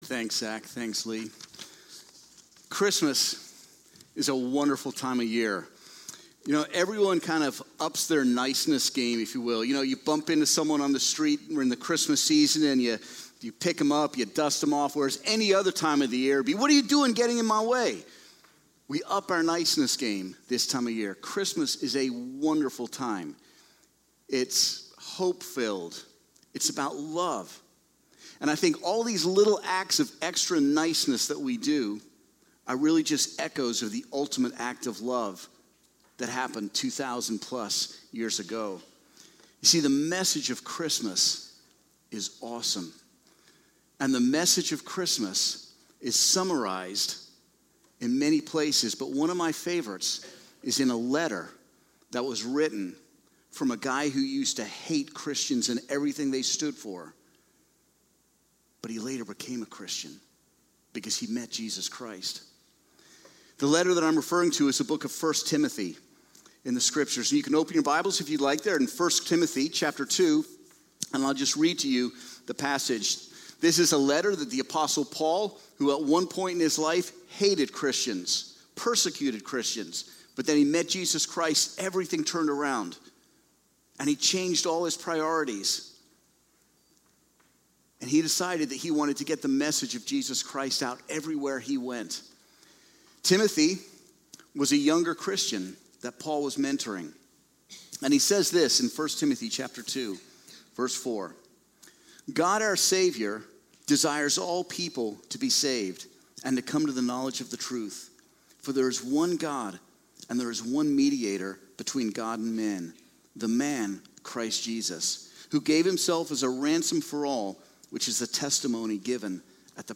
Christmas Eve
Sermon